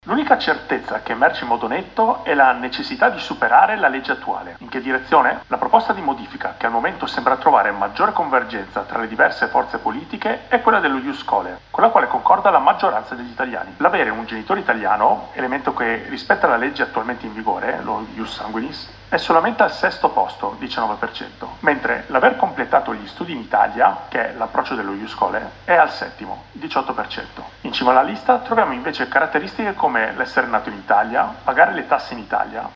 Dopo oltre due settimane dalle alluvioni in Libia la situazione è ancora molto delicata. Il servizio